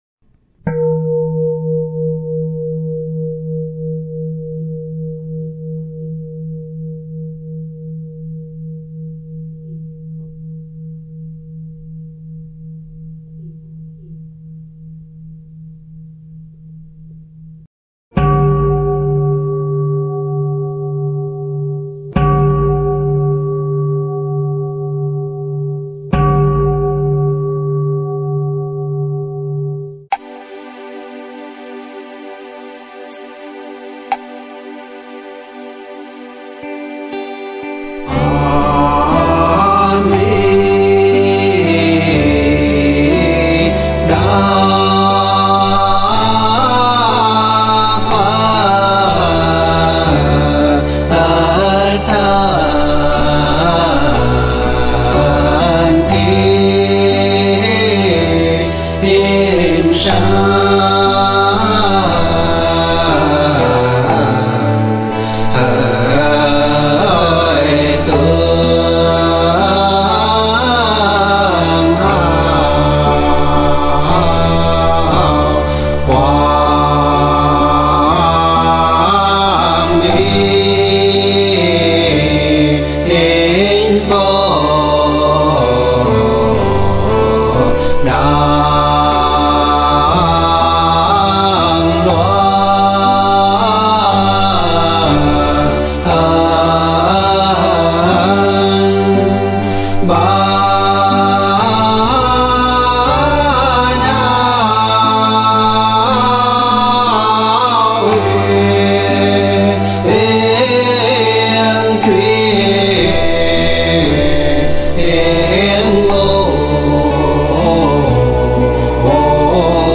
Thể loại: Nhạc Niệm Phật